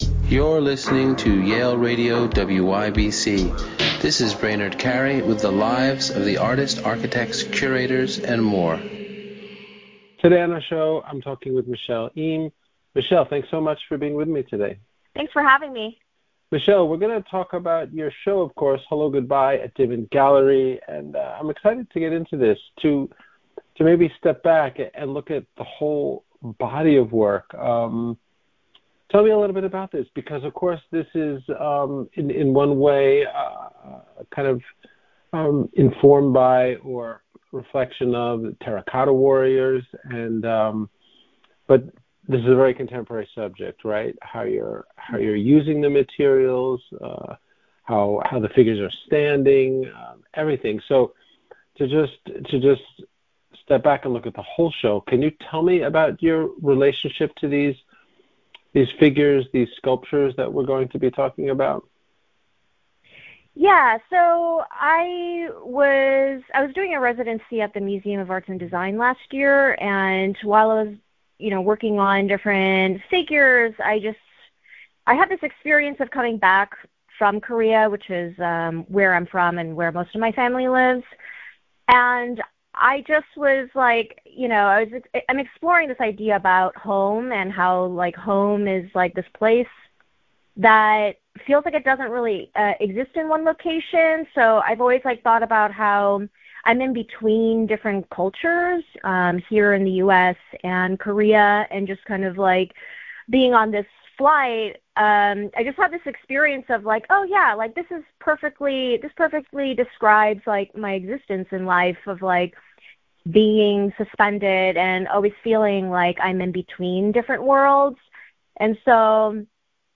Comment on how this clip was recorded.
Interviews from Yale University Radio WYBCX